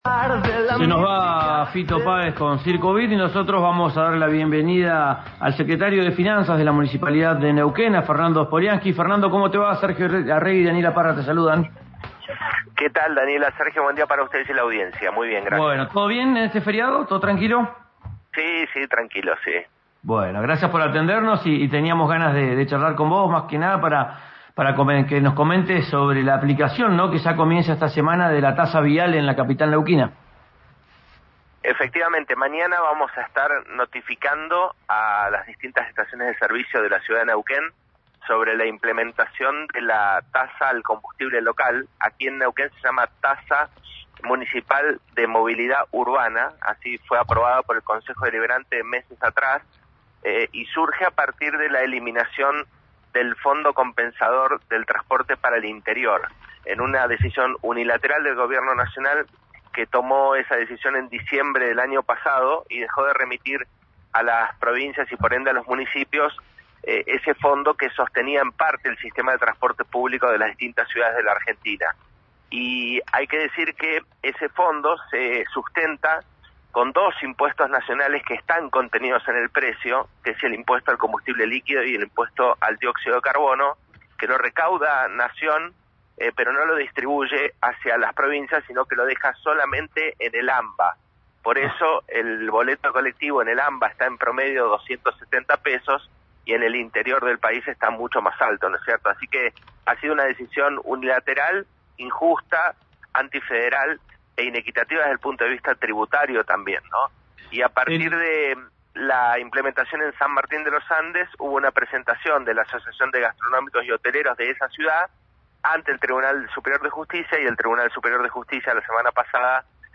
Escuchá al secretario de Finanzas en RÍO NEGRO RADIO